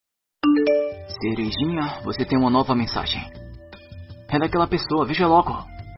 Sons de notificação Otaku
Sons de notificação Anime Som de notificação com a voz do Sasuke
Categoria: Toques